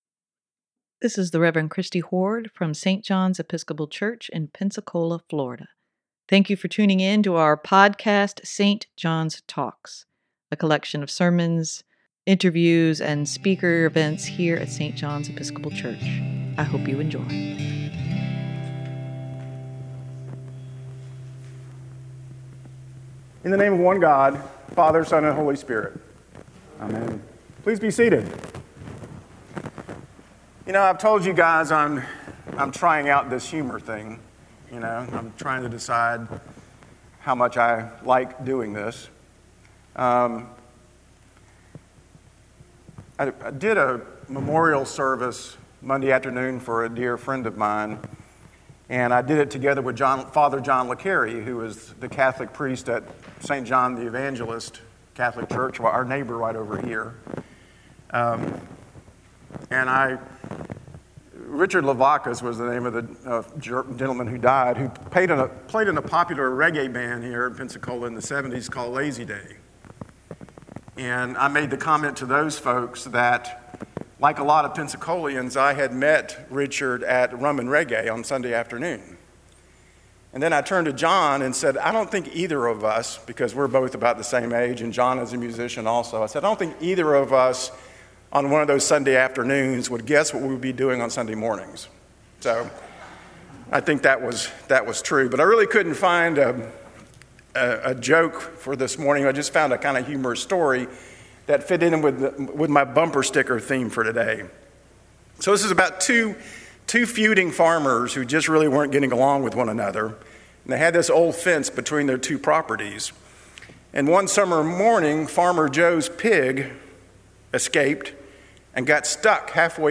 Sermon for June 22, 2025: Grace crosses boundaries - St. John's Episcopal Church, Pensacola, Florida